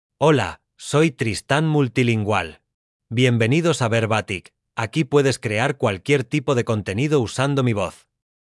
Tristan Multilingual — Male Spanish (Spain) AI Voice | TTS, Voice Cloning & Video | Verbatik AI
Tristan Multilingual is a male AI voice for Spanish (Spain).
Voice sample
Male
Tristan Multilingual delivers clear pronunciation with authentic Spain Spanish intonation, making your content sound professionally produced.